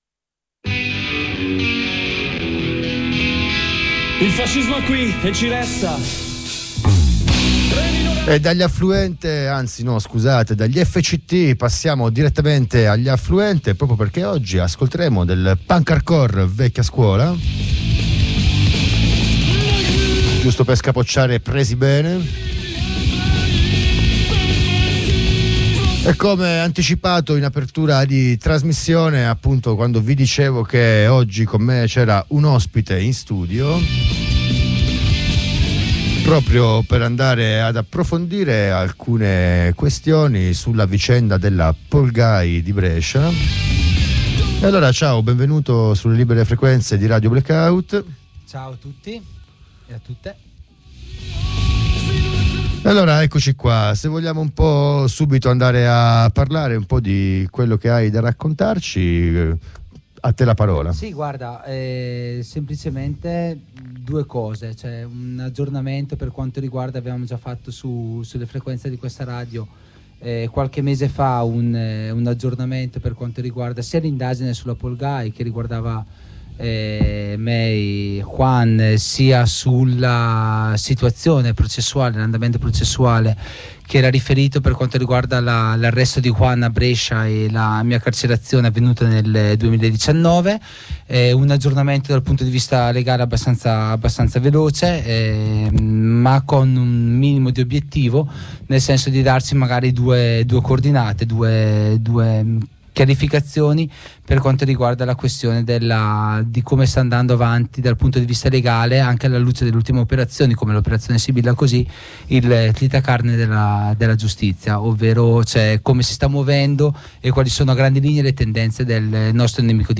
Ospite in studio ai microfoni di ACAB